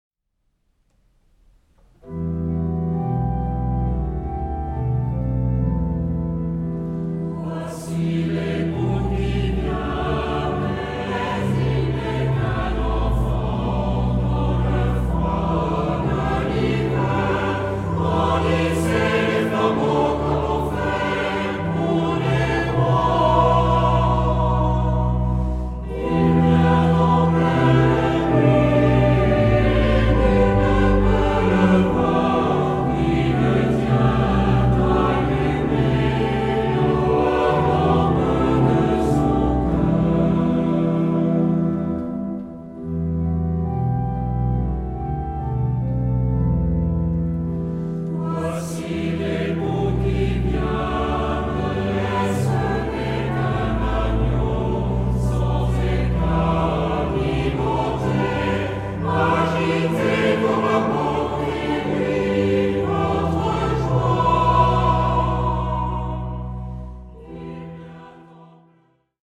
SATB (4 voices mixed).
Hymn (sacred).